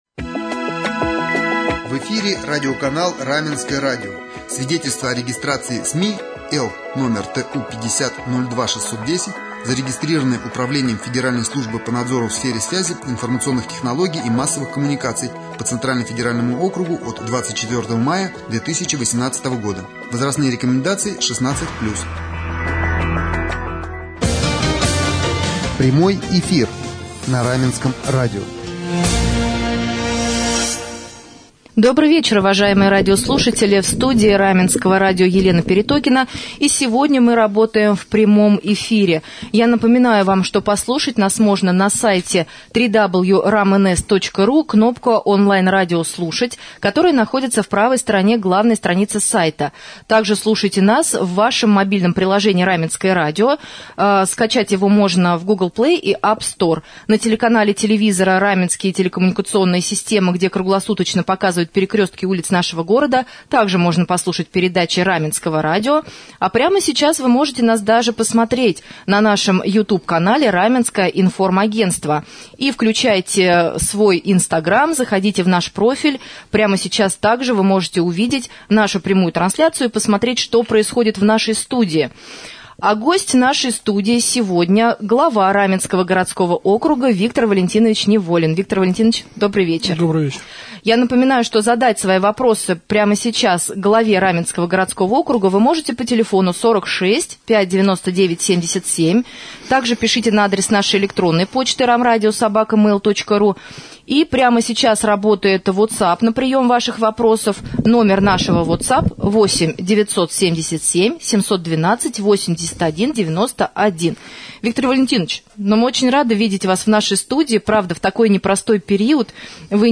Виктор Неволин, глава Раменского г.о. принял участие в прямом эфире на Раменском радио 17 июня и ответил на волнующие жителей вопросы.